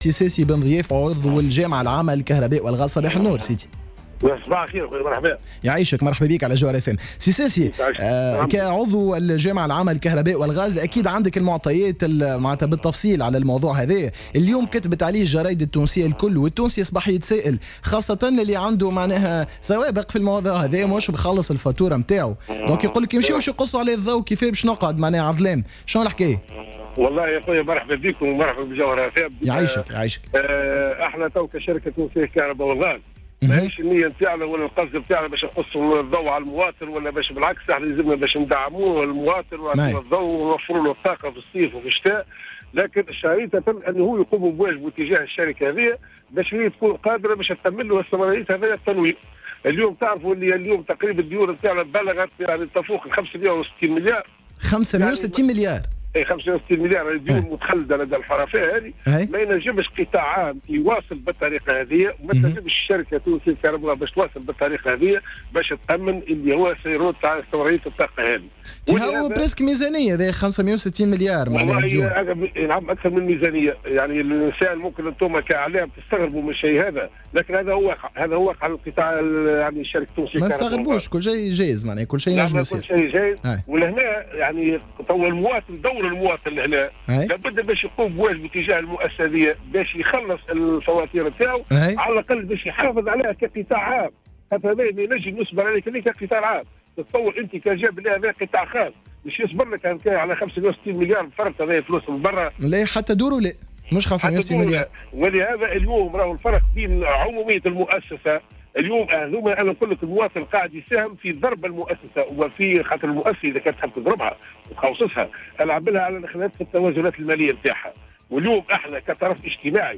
في مداخلة له على جوهرة اف ام...